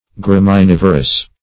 Search Result for " graminivorous" : The Collaborative International Dictionary of English v.0.48: Graminivorous \Gram"i*niv"o*rous\, a. [L. gramen, graminis, grass + vorare to eat greedily.] Feeding or subsisting on grass, and the like food; -- said of horses, cattle, and other animals.